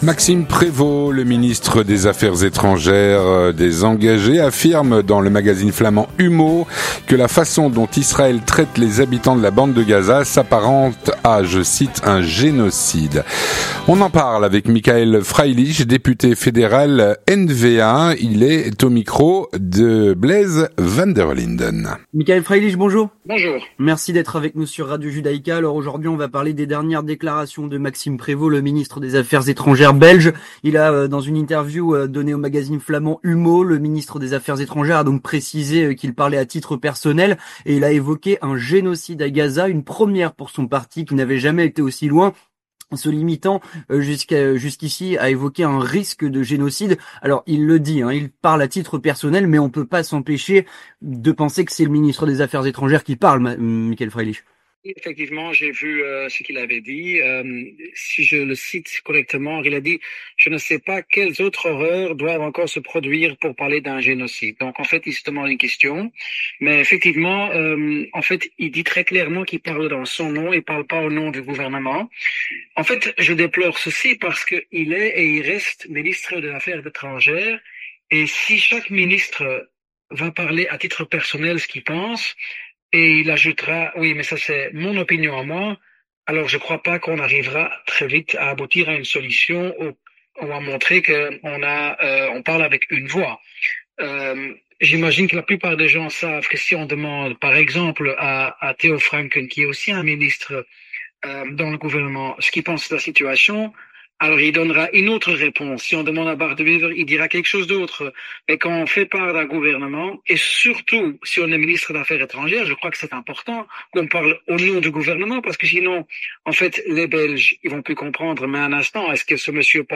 On en parle avec Michaël Freilich, député fédéral NVA.